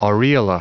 Prononciation du mot aureola en anglais (fichier audio)
Prononciation du mot : aureola